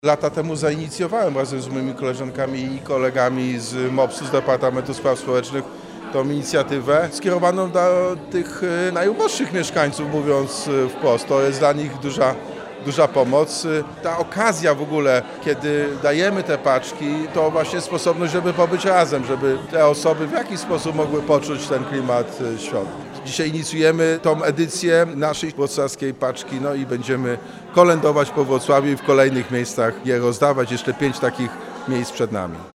Takie paczki to realna pomoc, ale również możliwość spotkania się i spędzenia czasu razem – zaznacza Jacek Sutryk, Prezydent Wrocławia.